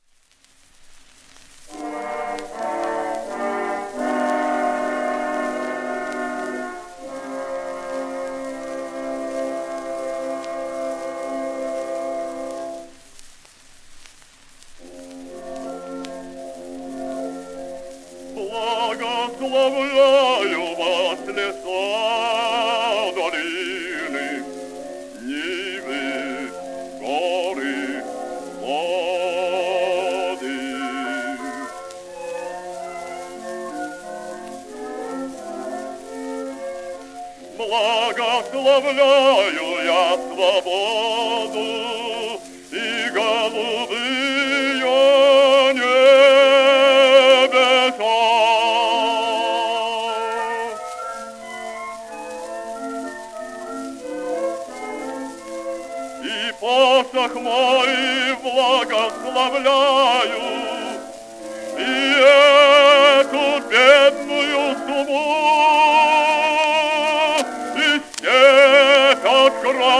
フョードル・シャリアピン(Bass:1873-1938)
w/オーケストラ
1924年頃録音
旧 旧吹込みの略、電気録音以前の機械式録音盤（ラッパ吹込み）